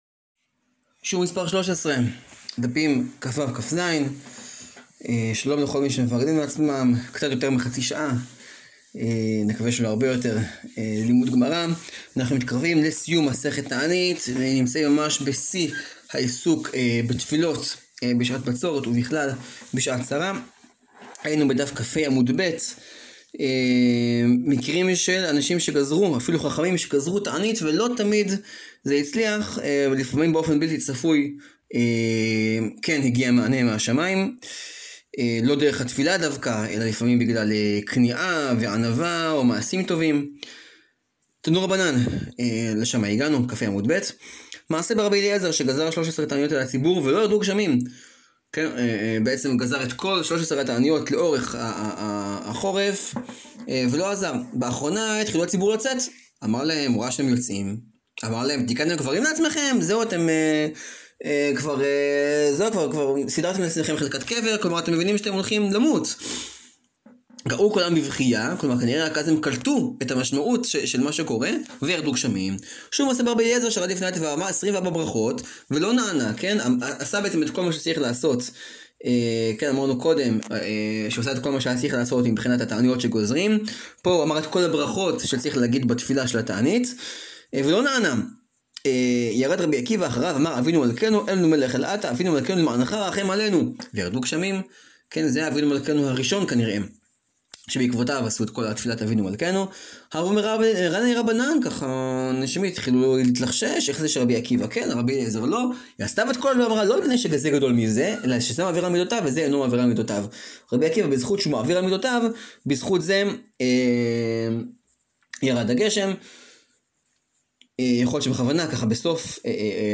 שיעור 13 להאזנה: מסכת תענית, דפים כו-כז.